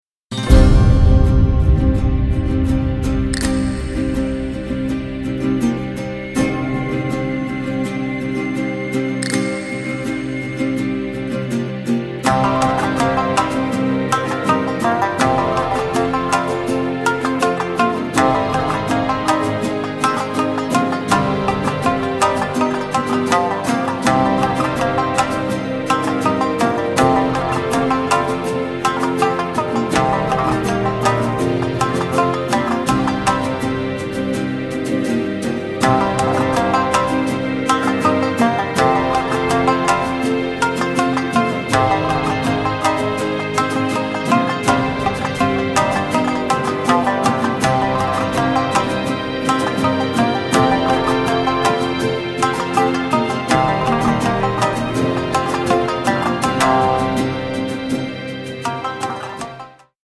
無限とも思えるほどの奥深い日本文化の神髄を洋楽器と共に表現し、日本人のDNAの中にある「和の心」を震わせる一枚。